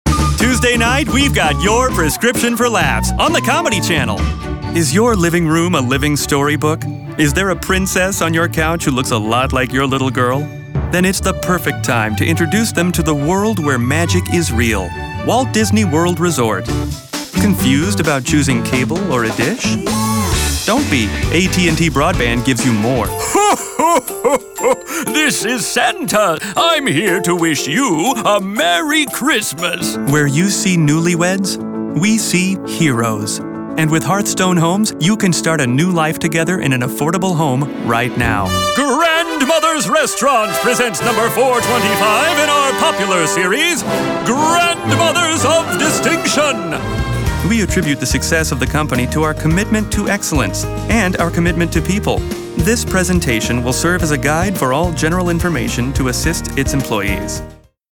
COMMERCIAL/ INDUSTRIAL/VOICEOVER
VOICEOVER DEMO(click here)